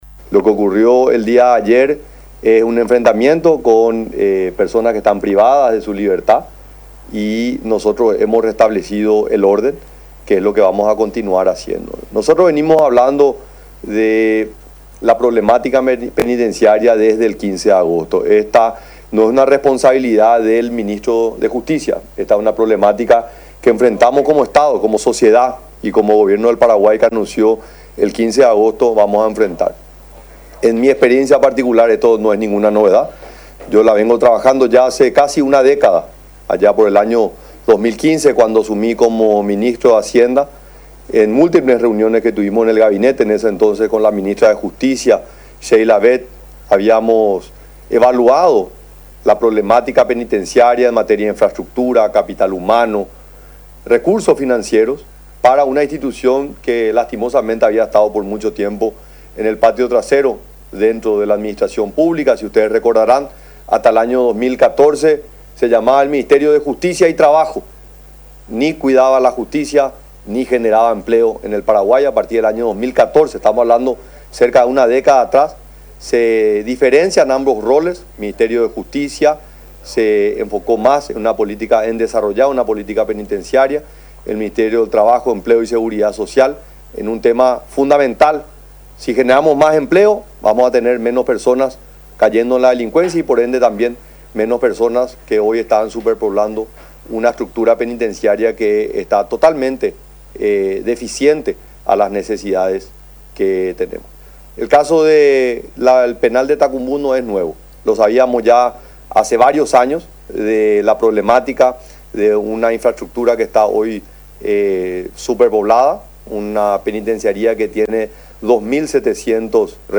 El presidente de la República, Santiago Peña, negó rotundamente que lo sucedido en la Penitenciaria de Tacumbú se trate de una crisis y apuntó a un enfrentamiento con la delincuencia. En la conferencia convocada en Mburuvicha Róga, estuvieron presentes varios ministros, entre ellos, Enrique Riera y Ángel Barchini, quienes no brindaron declaraciones.
21-CONFERENCIA-DE-PRESA-DE-SANTIAGO-PENA.mp3